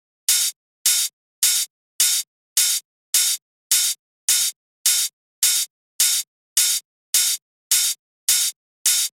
第7天堂的开放性Hihat
描述：来自我的Roland Groovebox 505的原版开放hihat声音
Tag: 105 bpm Dance Loops Drum Loops 1.54 MB wav Key : Unknown